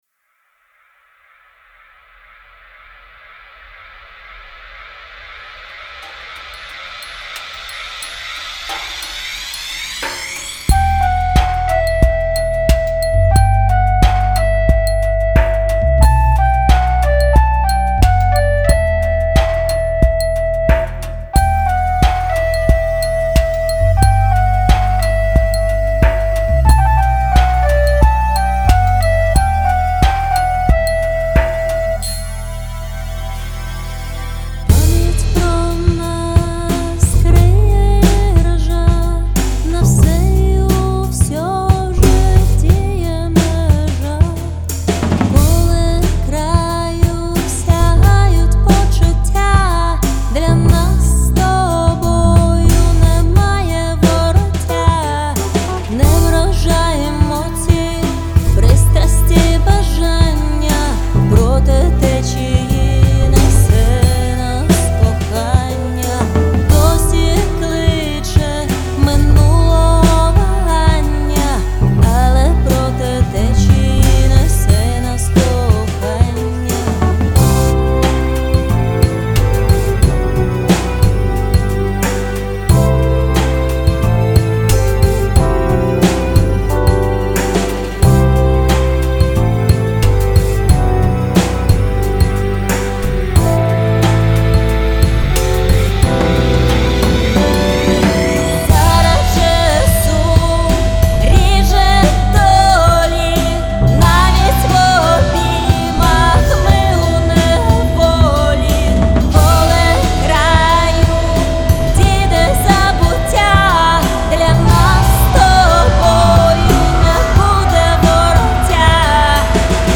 СТИЛЬОВІ ЖАНРИ: Ліричний